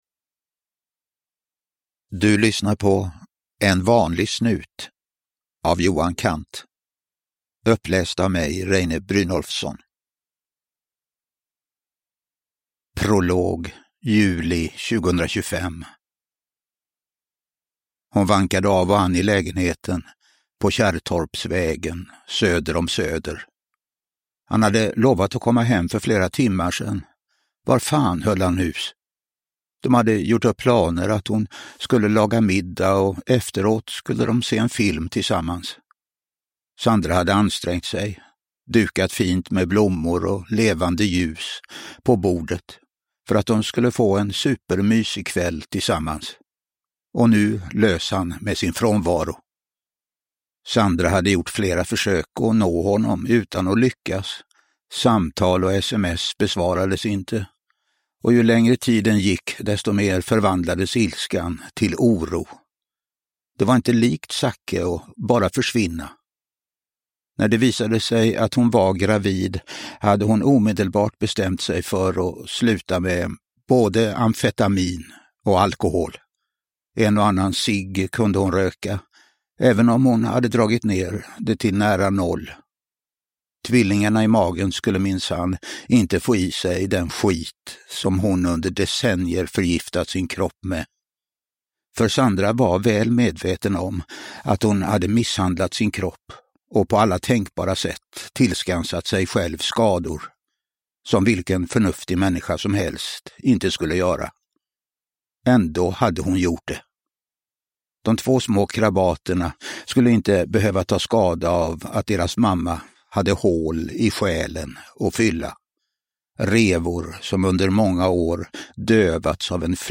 En vanlig snut (ljudbok